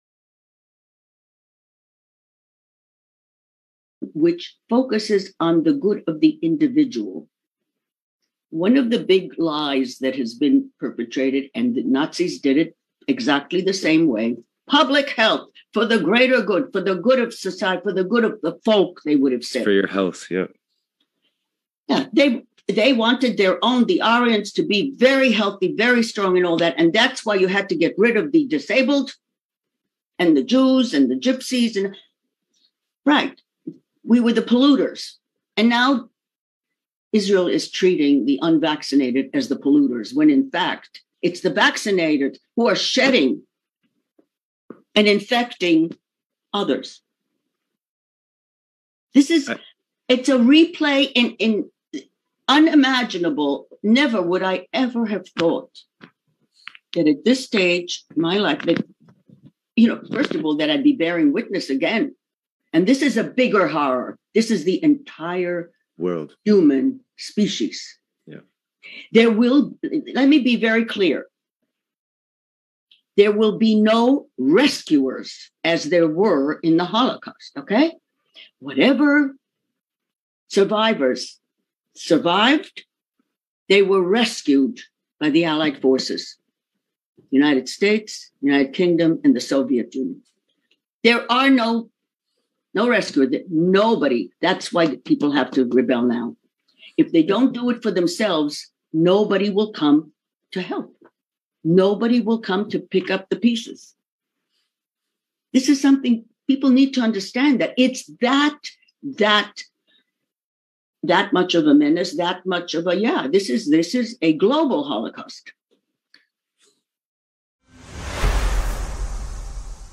ניצולת שואה מרומניה